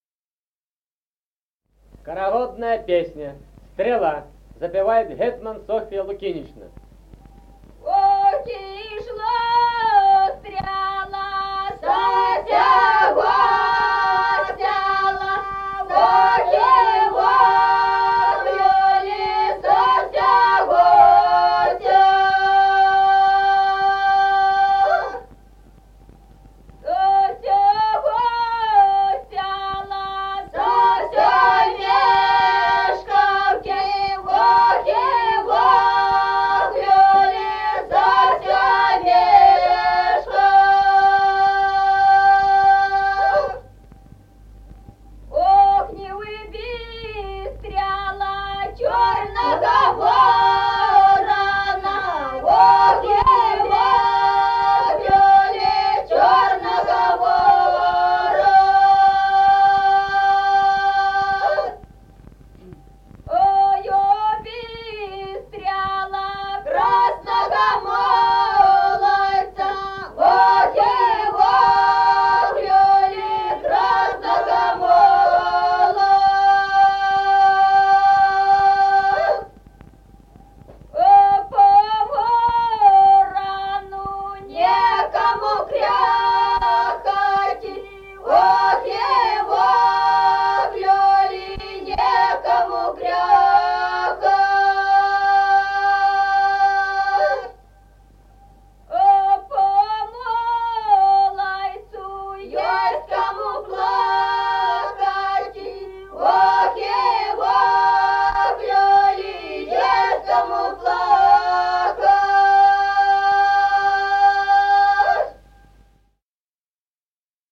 Музыкальный фольклор села Мишковка «Ох, ишла стрела», юрьевская.